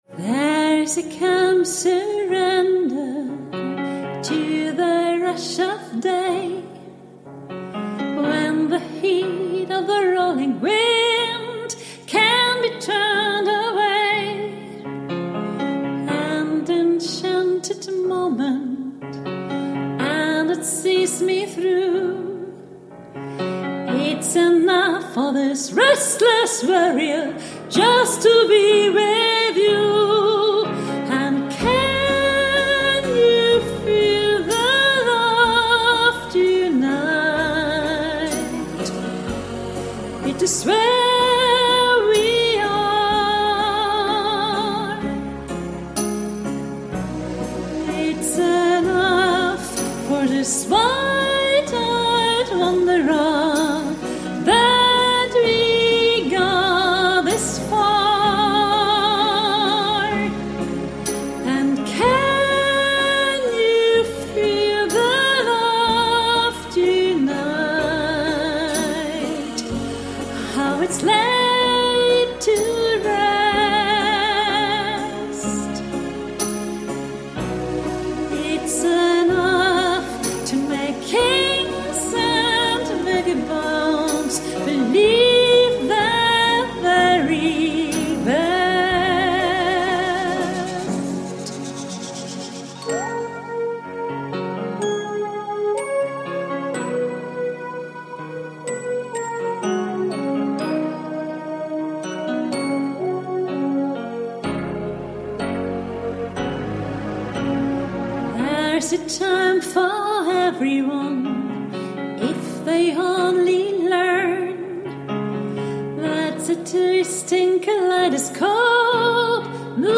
Hochzeitssängerin Hannover/Niedersachsen